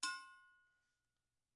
VSCO 1打击乐图书馆 金属 " 刹车（制动）mp
Tag: 金属 打击乐器 制动器 VSCO -2 单票据 多重采样